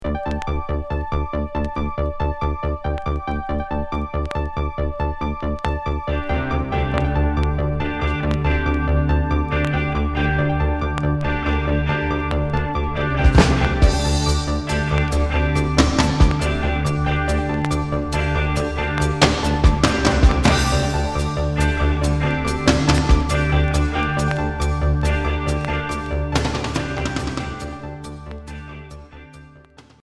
Cold new wave Unique 45t retour à l'accueil